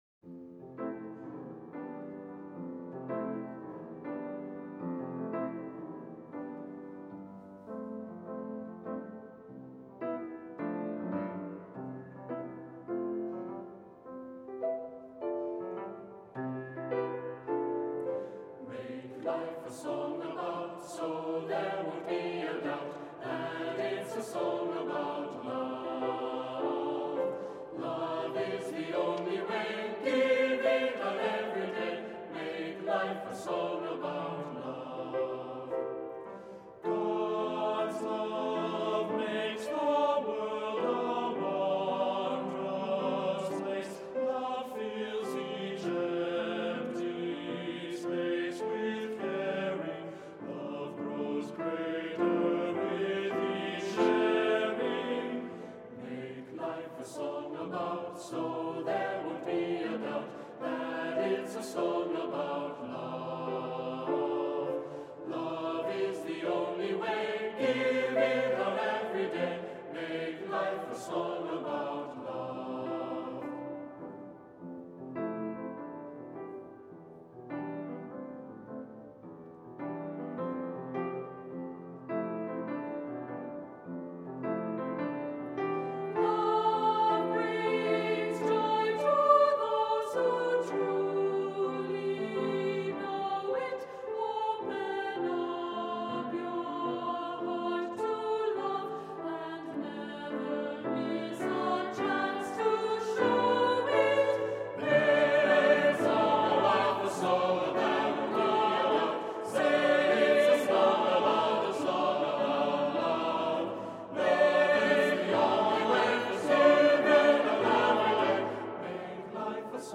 Voicing: Unison with descant